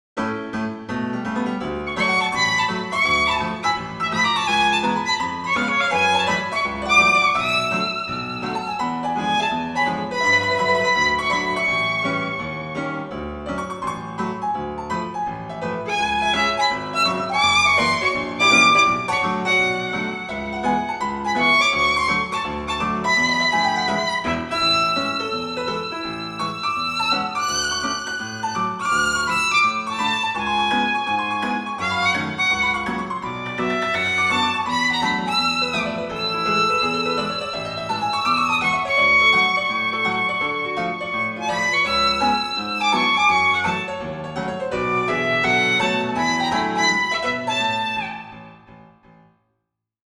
●デモソングのグラフ（弦楽器）
木管楽器と同様、Vibrato Randomの高値を局所的に併用しています。
短い音にも一瞬だけ速く深いビブラートを掛けている箇所があります。
後半のフレーズのように、大きな山の中に更に音型ごとに小さな山をつくると、ビブラートが連続する箇所でも平坦な印象を与えません。
cup_vn_vibd.mp3